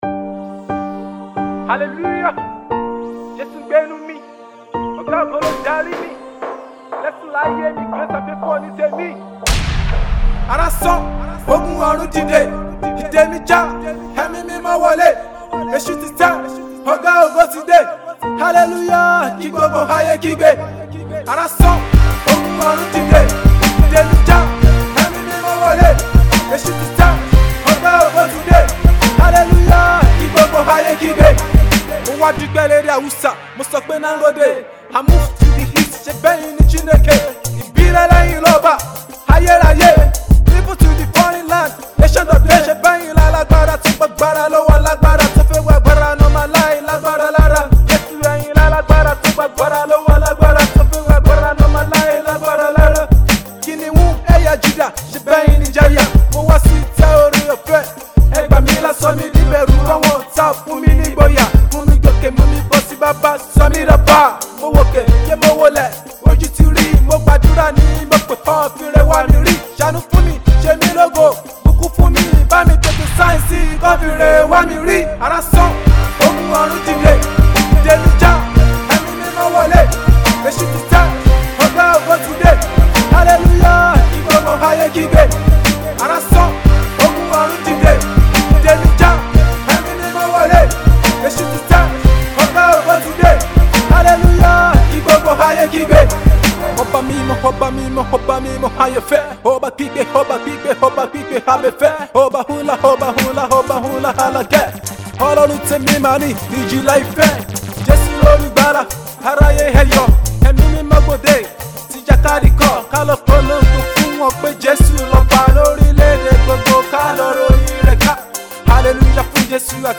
Gospel Rap
a rap song with rich lyrics